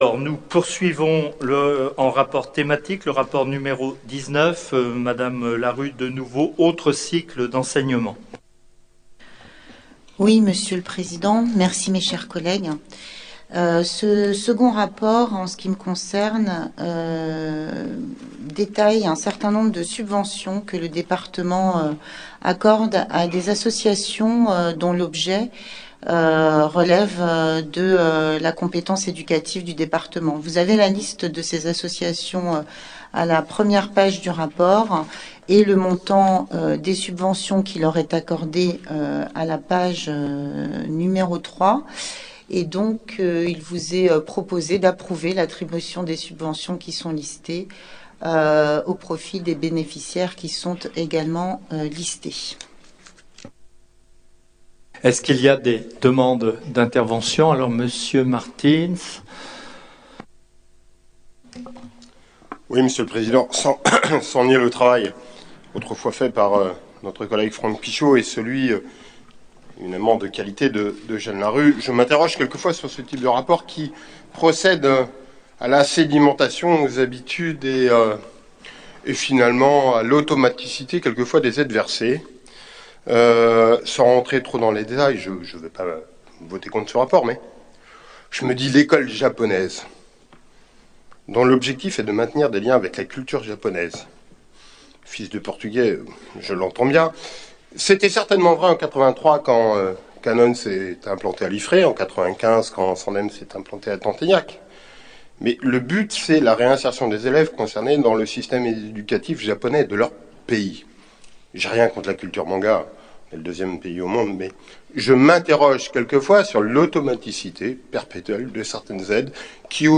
Assemblée départementale